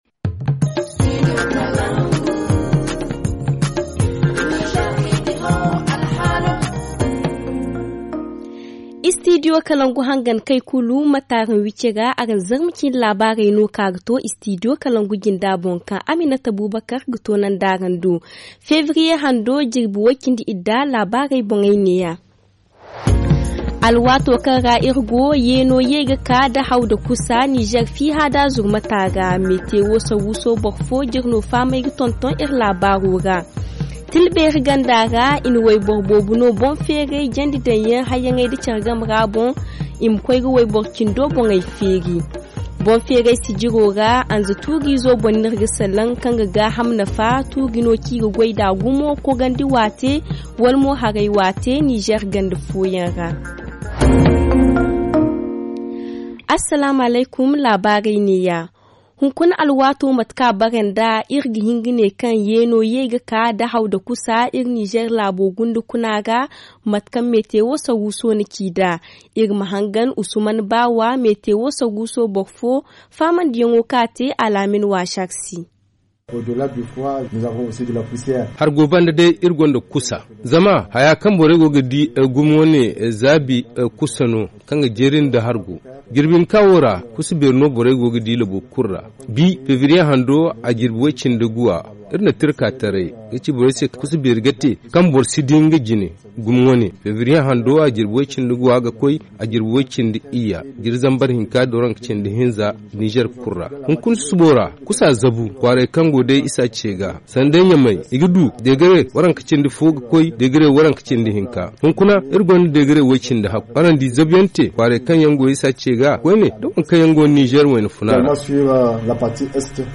Le journal du 16 février 2023 - Studio Kalangou - Au rythme du Niger